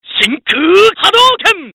Play, download and share SHINKUU HADOUKEN! original sound button!!!!